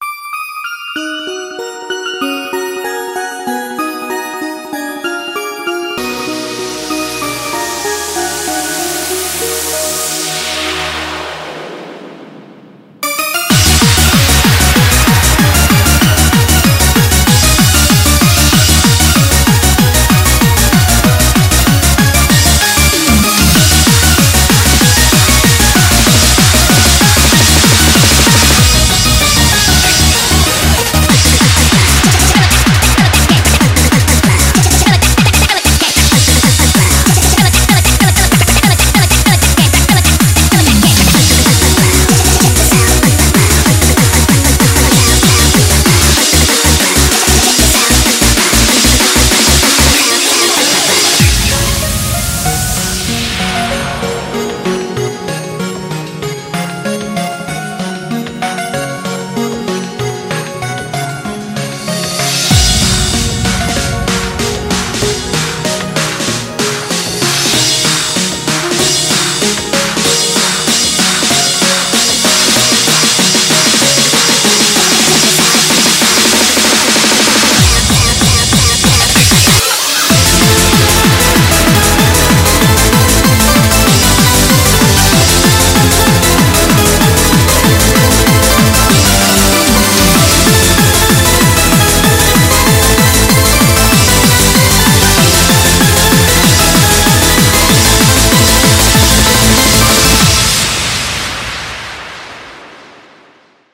BPM191
Audio QualityPerfect (High Quality)
S4 - Speed of Nature 191 BPM